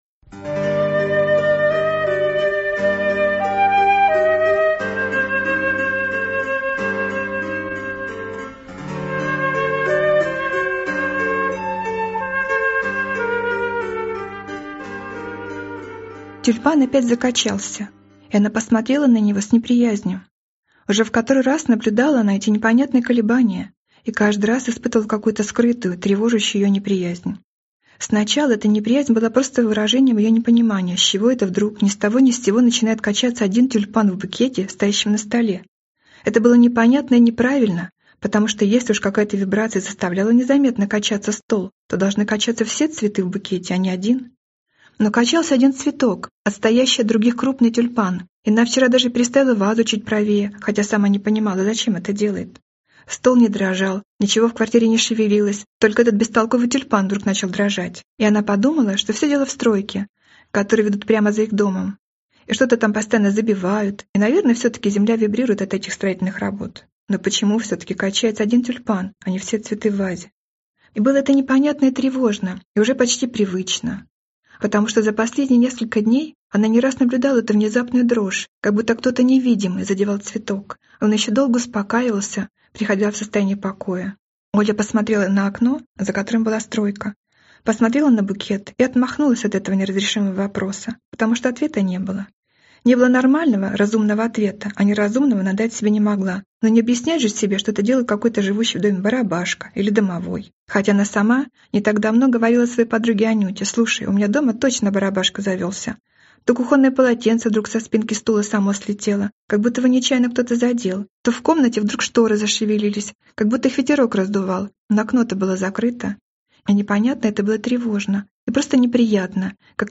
Аудиокнига Прощание с росой | Библиотека аудиокниг